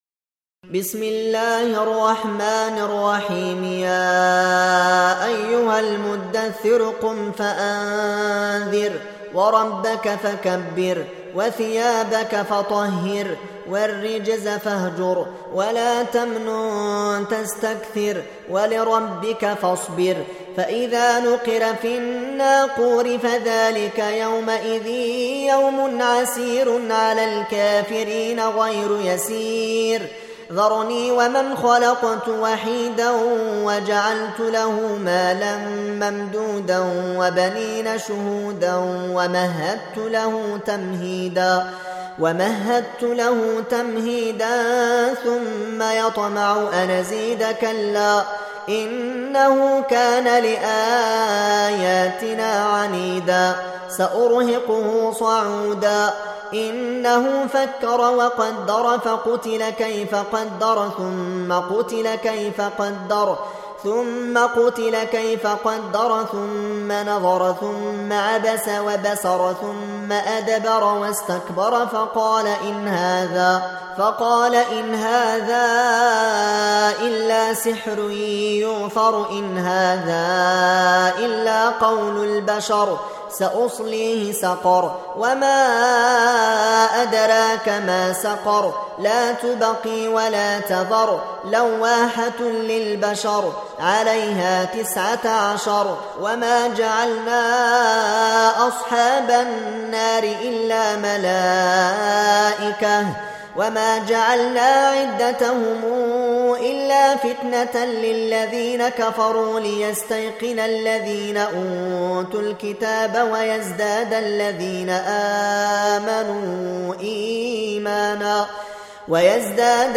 Audio Quran Tarteel Recitation
Surah Sequence تتابع السورة Download Surah حمّل السورة Reciting Murattalah Audio for 74. Surah Al-Muddaththir سورة المدّثر N.B *Surah Includes Al-Basmalah Reciters Sequents تتابع التلاوات Reciters Repeats تكرار التلاوات